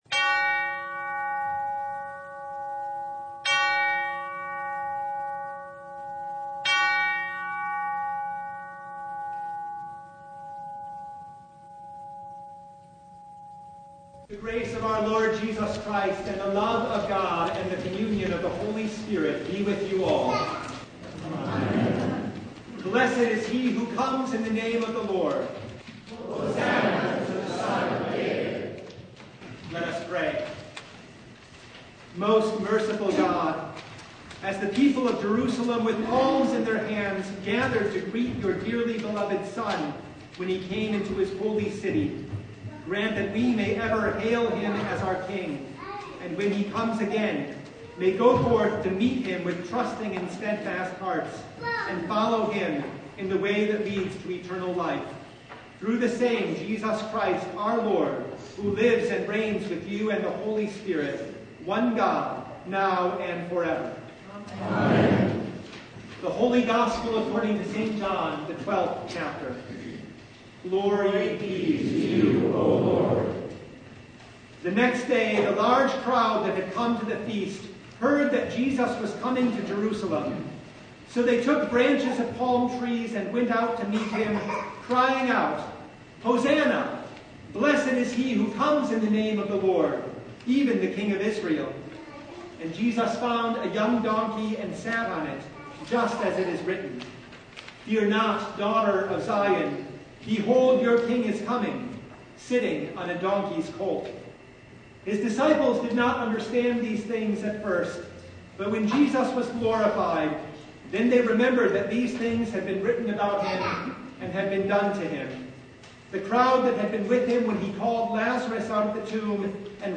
Matthew 27:15-26 Service Type: Palm Sunday Expectations are high as Jesus enters Jerusalem.